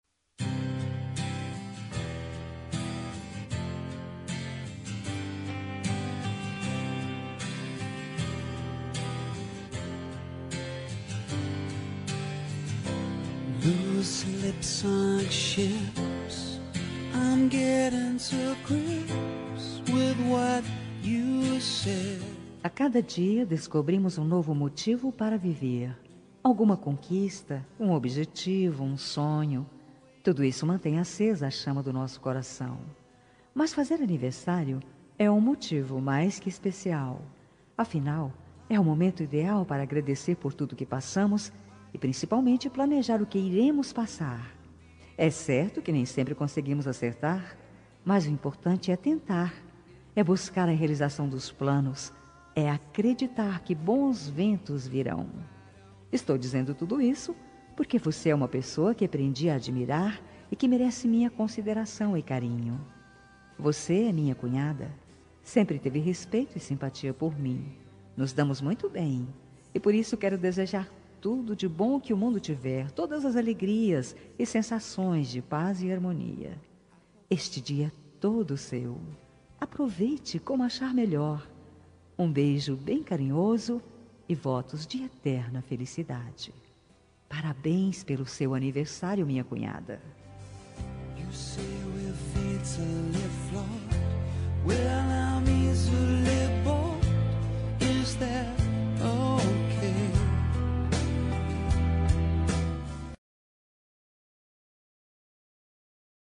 Aniversário de Cunhada – Voz Feminina – Cód: 2634